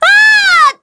Priscilla-Vox_Dead_kr.wav